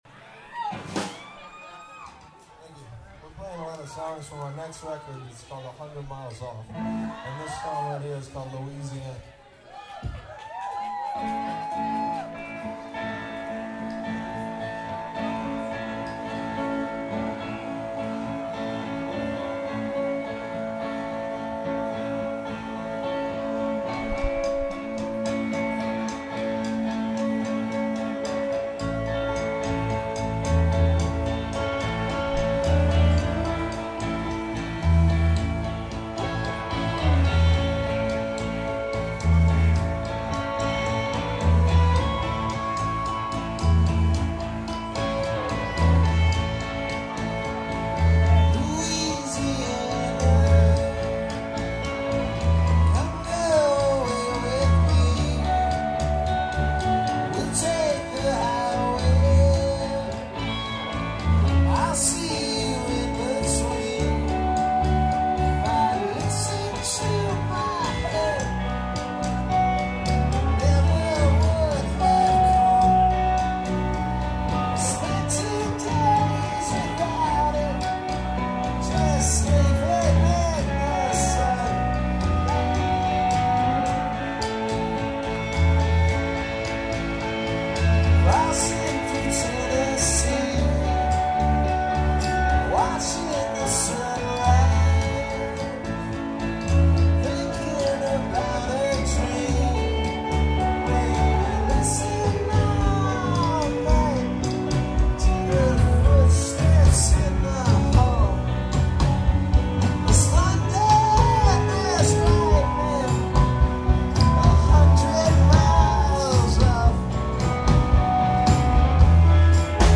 This track comes from a show in Columbus last month.